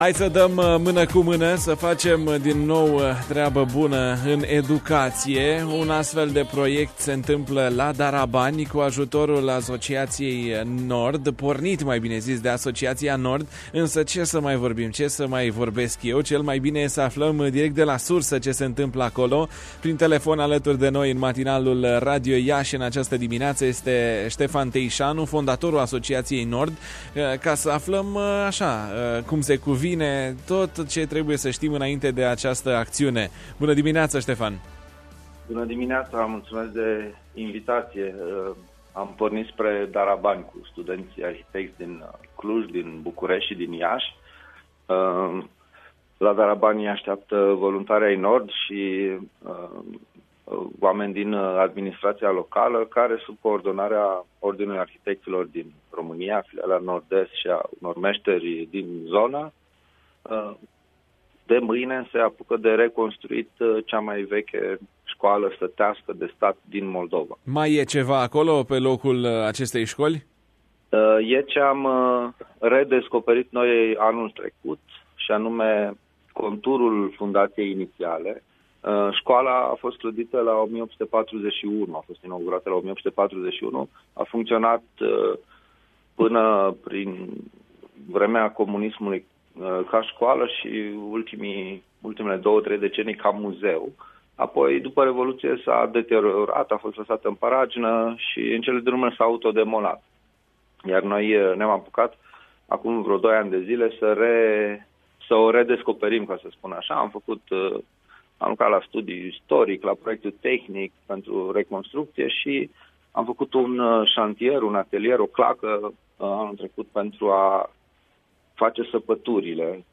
în matinalul Radio Iași și a explicat motivația care a stat în spatele proiectului.